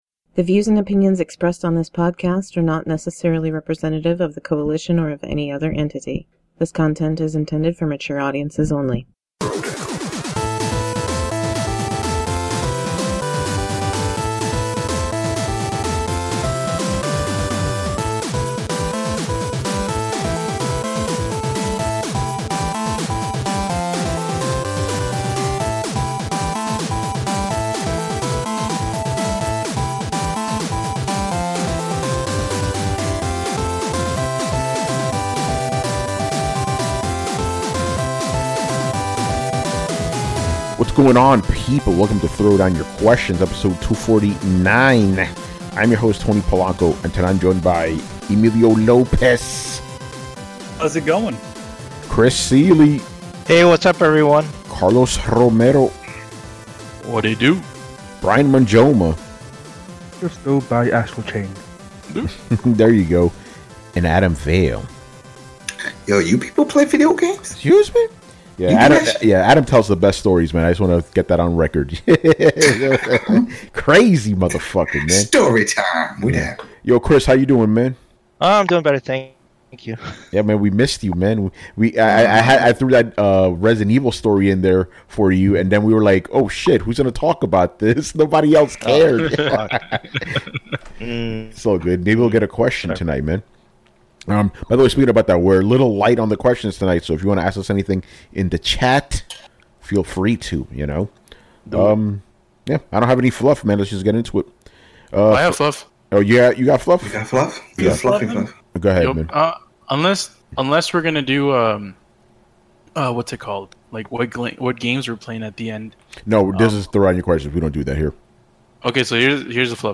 On this show, we answer all of your Video Game related questions.